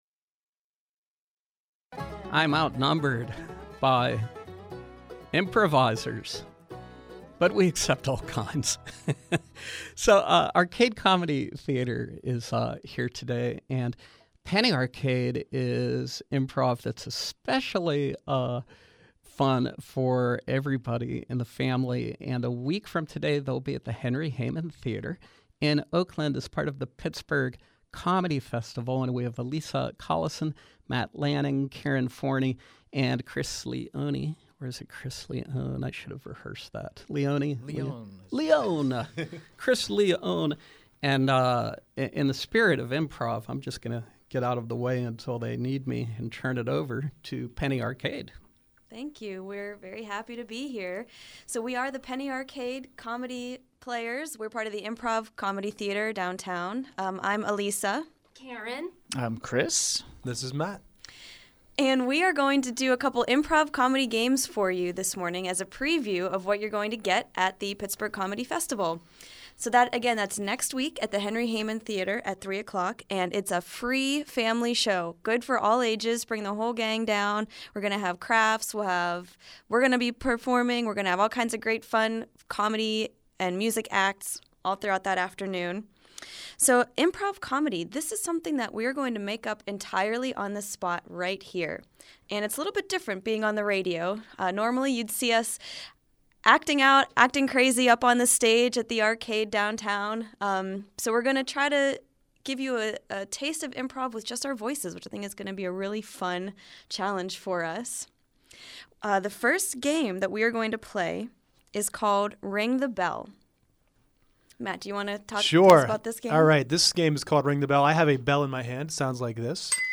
Live Performance: The Penny Arcade
From 8-22-14: Improv comedy with Arcade Comedy Theater’s Penny Arcade in advance of their 8/29 performance at the Kids Comedy Cabaret at the Pittsburgh Comedy Festival, Henry Heymann Theater, Oakland.